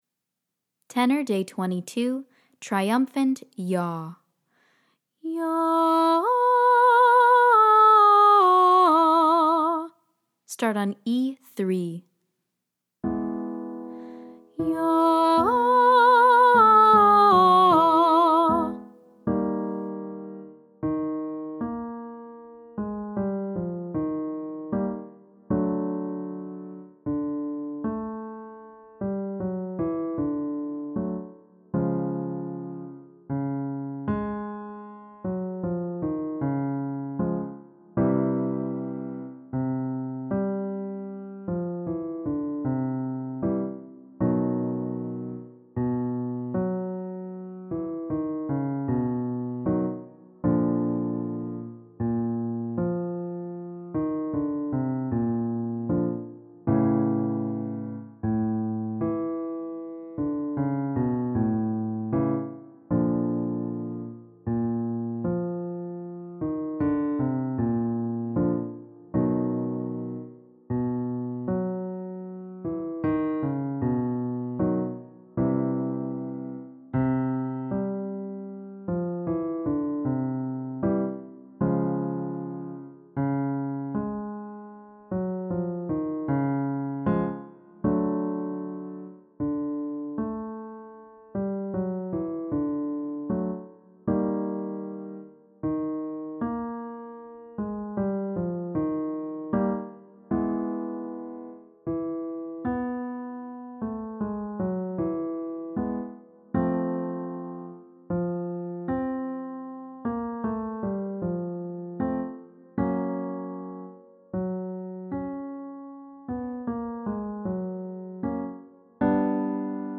Day 22 - Tenor - Vibrato Practice